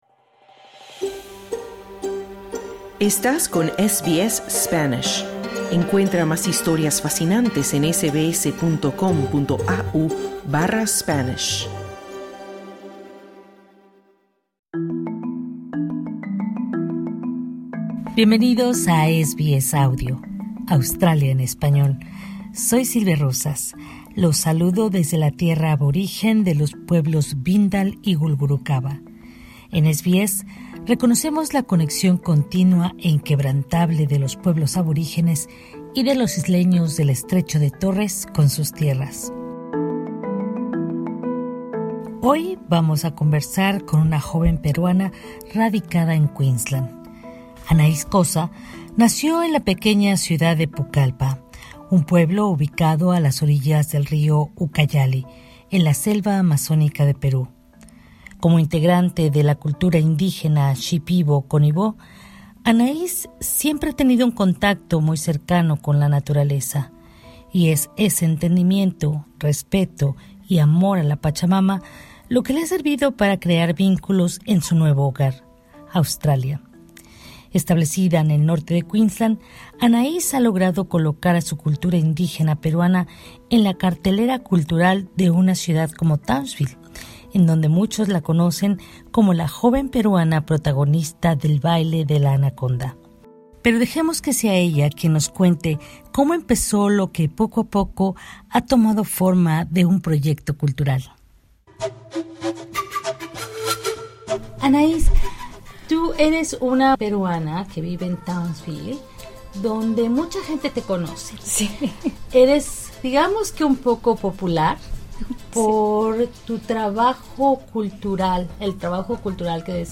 Escucha la entrevista completa en el podcast que se encuentra al inicio de la página.